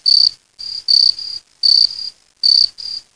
cricket.wav
1 channel
cricket.mp3